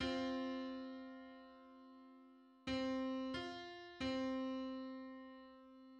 File:Hundred-seventy-first harmonic on C.mid - Wikimedia Commons
Hundred-seventy-first_harmonic_on_C.mid.mp3